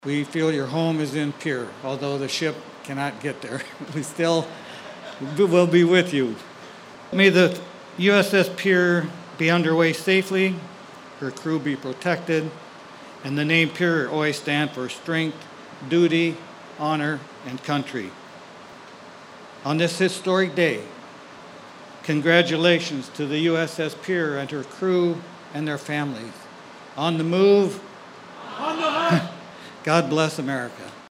PANAMA CITY, F.L.(KCCR)- The U-S-S Pierre officially joined the fleet of the United States Navy Saturday morning with a traditional Commissioning Ceremony at Port Panama City Florida.
Pierre Mayor Steve Harding says the Crew of the Pierre will always have a second home in South Dakota’s State Capitol.